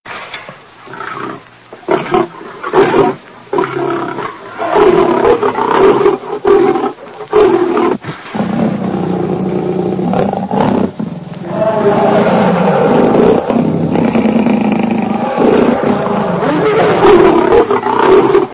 دانلود صدای شیر از ساعد نیوز با لینک مستقیم و کیفیت بالا
جلوه های صوتی